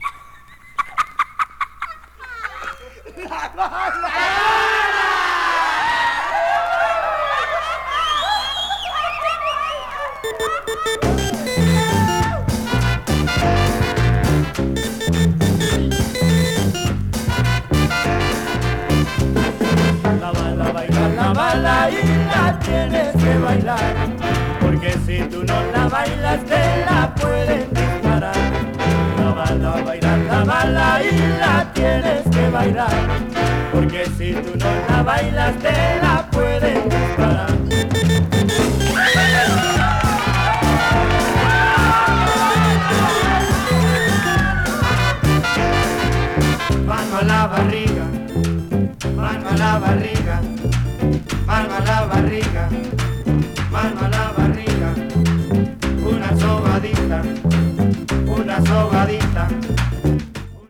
チープな高音のオルガンがとても特徴的！